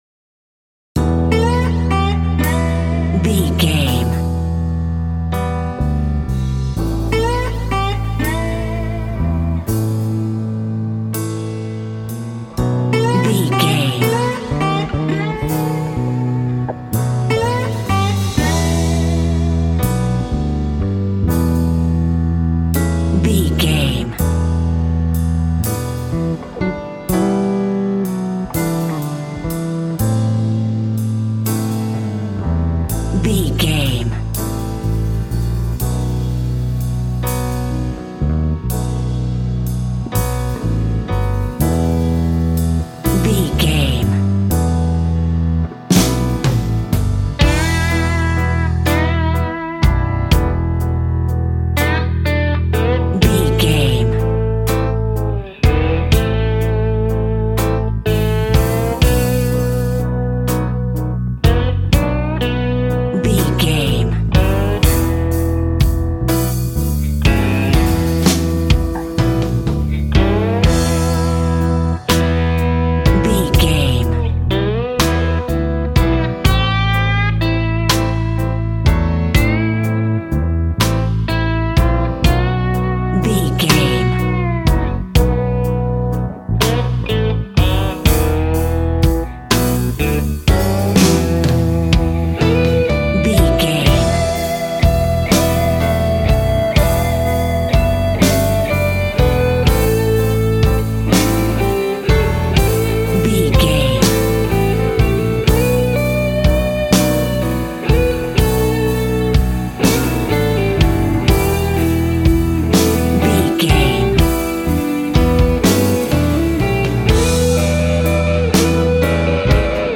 Aeolian/Minor
sad
mournful
bass guitar
electric guitar
electric organ
drums